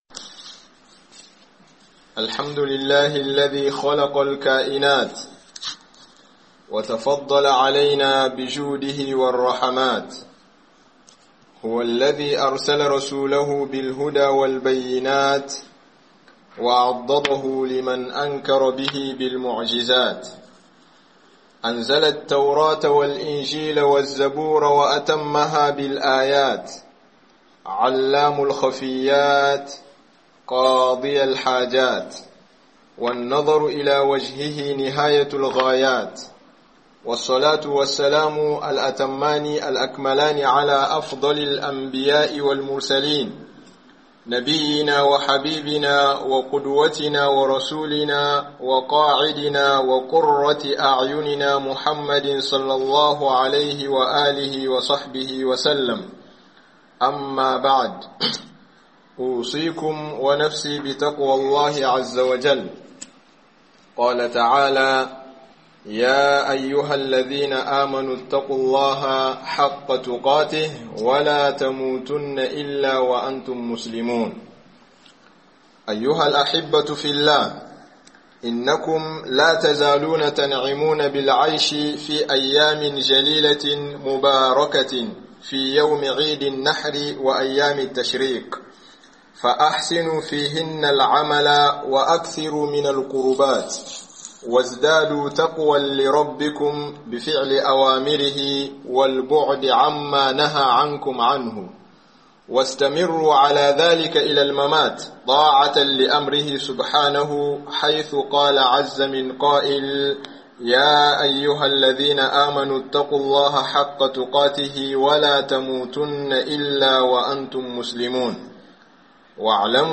Haɗuwar idi da juma'a (layya 2025) - Hudubobi